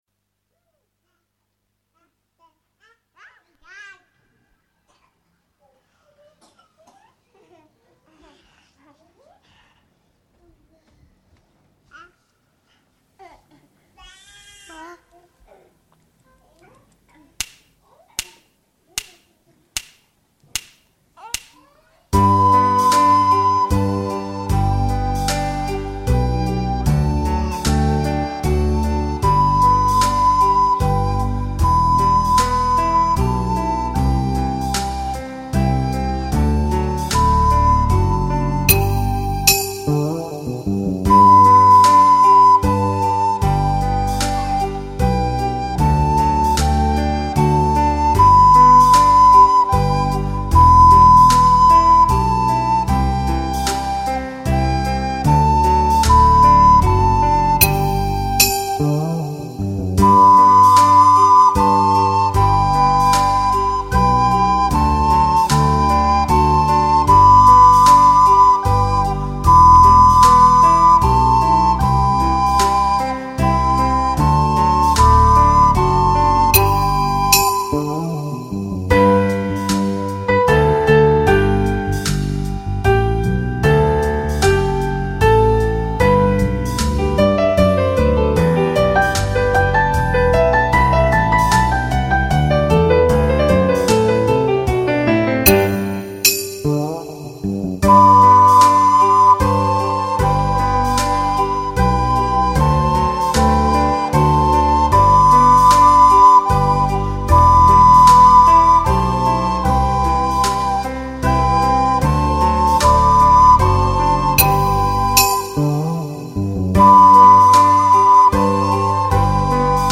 Audicions de flauta